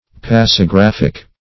Search Result for " pasigraphic" : The Collaborative International Dictionary of English v.0.48: Pasigraphic \Pas`i*graph"ic\, Pasigraphical \Pas`i*graph"ic*al\a. Of or pertaining to pasigraphy.
pasigraphic.mp3